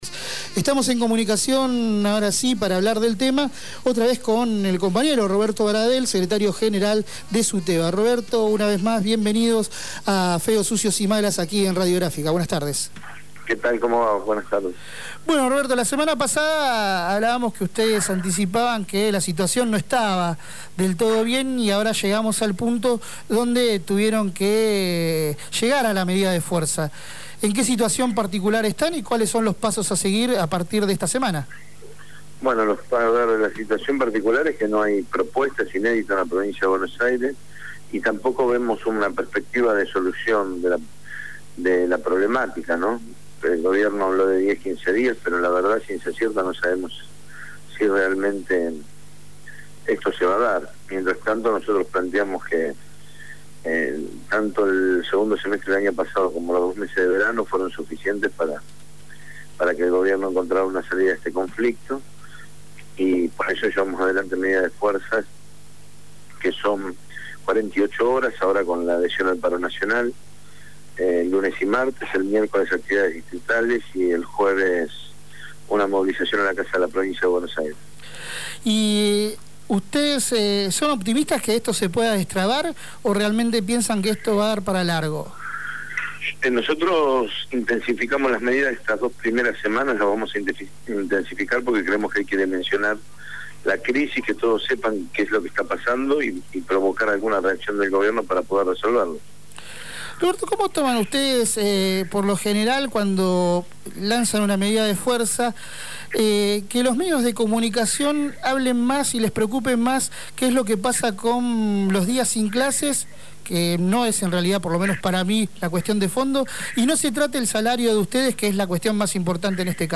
En contacto con Feos, Sucios y Malas, se refirió a la paritaria docente que atraviesa la provincia de Buenos Aires, e indicó que “no vemos perspectiva de solución”.